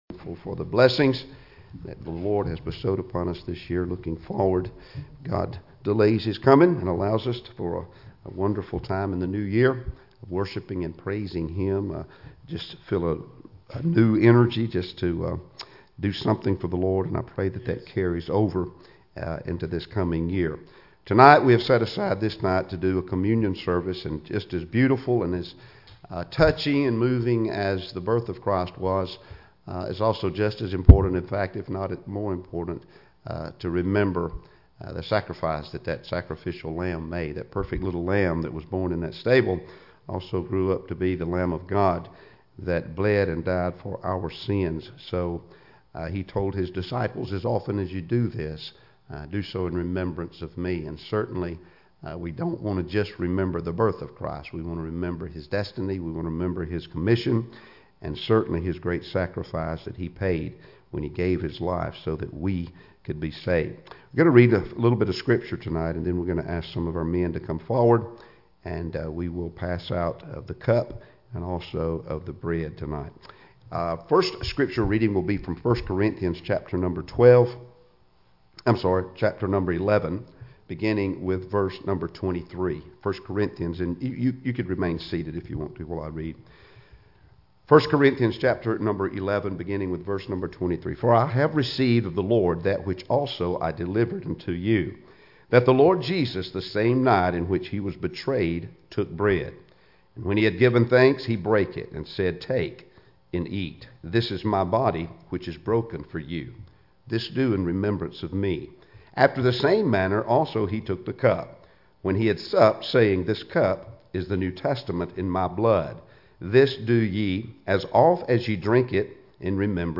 Communion Service